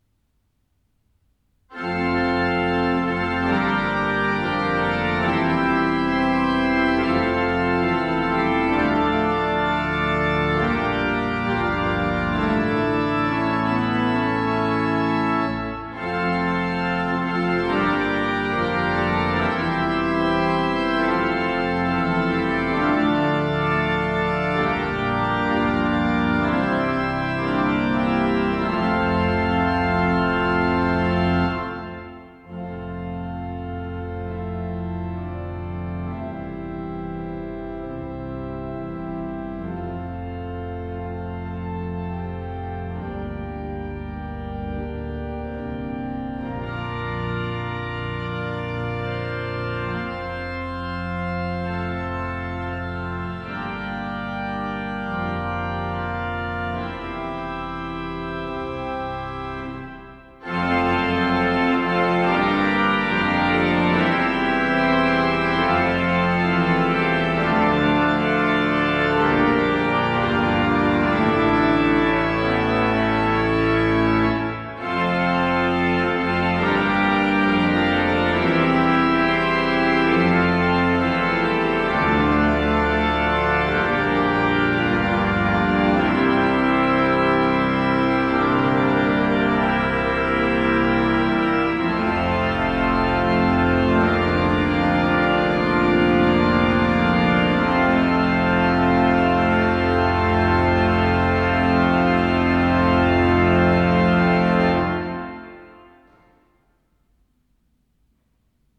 Orgelet er byg i fransk/tysk romantisk stil fra 1800 tallet med Cavaillè-Coll som inspirasjon for rørstemmer og intonasjon.
Fanfare Opptakene jeg gjorde under besøket i Levanger kirke.
Levanger kirke   ZOOM H4n Pro 05.09.2022
Fanfare-levanger.mp3